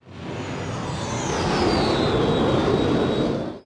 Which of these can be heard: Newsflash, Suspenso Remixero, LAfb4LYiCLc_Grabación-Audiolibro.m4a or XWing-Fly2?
XWing-Fly2